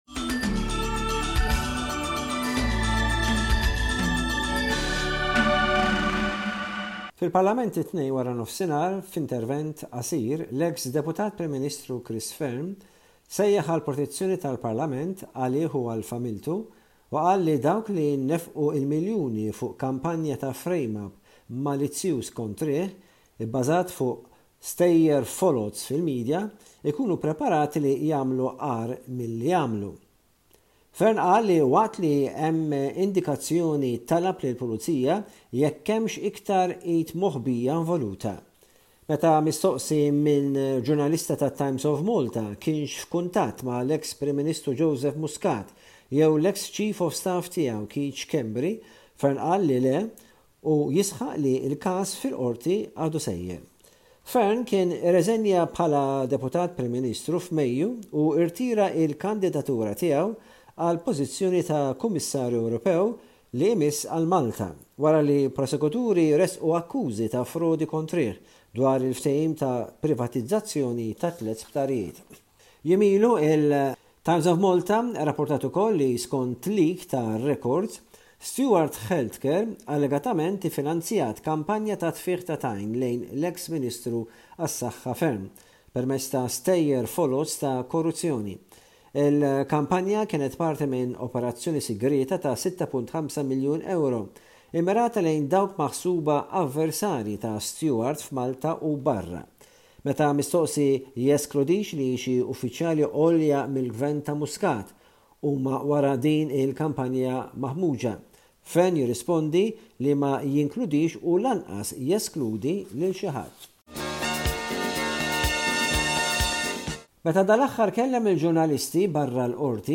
Bullettin ta' aħbarijiet minn Malta